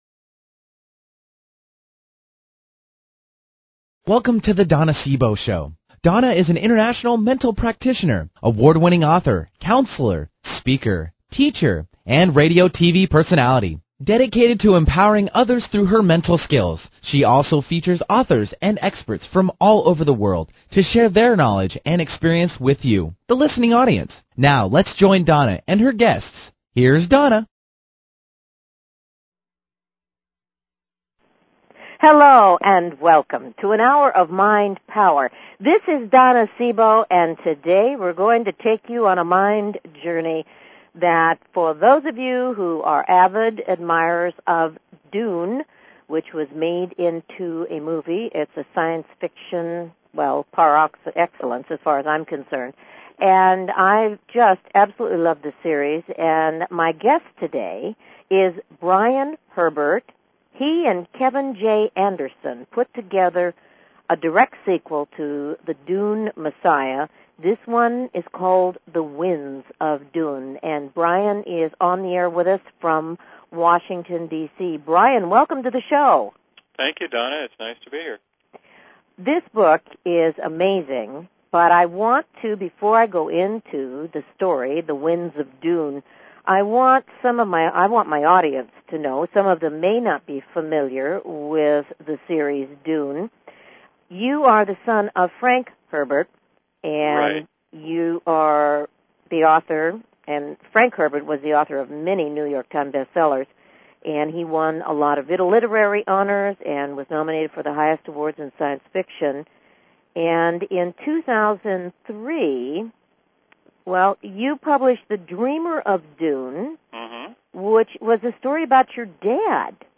Talk Show Episode
For all of you science fiction fans of 'Dune' you'll not want to miss this interview! The book is awesome and we'll be talking about secrets that up to now haven't been revealed.